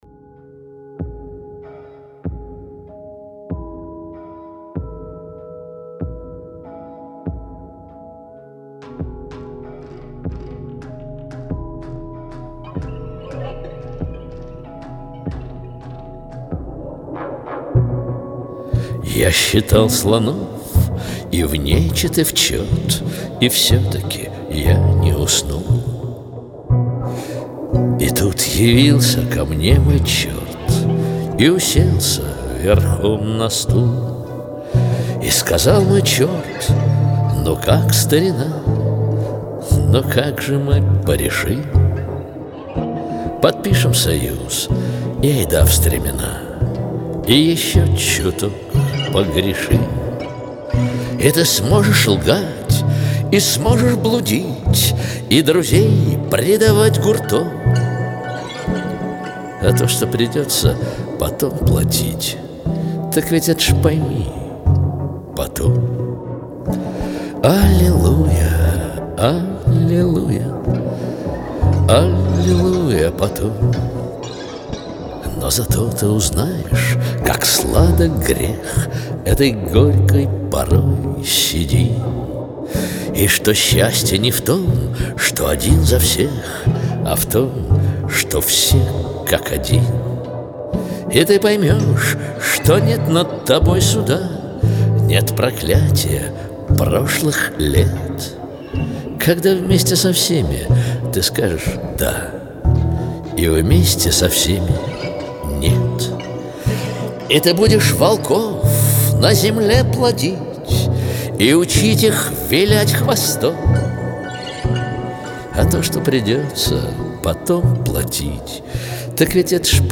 Жанр: авторская песня, rock